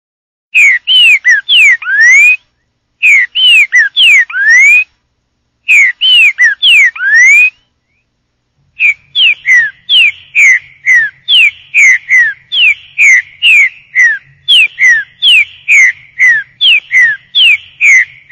Categoria Animais